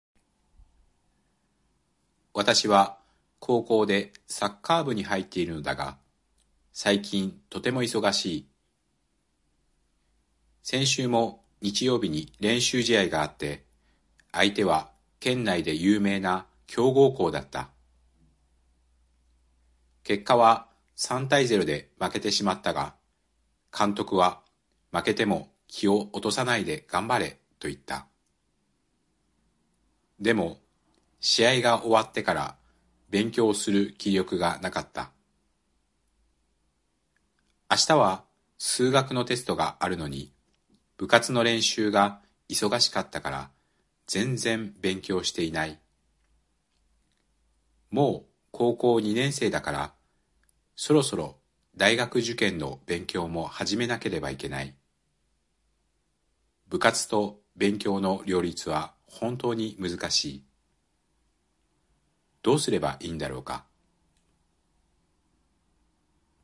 Alors, nous allons essayer une écoute rapide avec un enregistrement audio en japonais ci-dessous.
(Le niveau du texte est entre N4 et N3, à vitesse presque naturelle)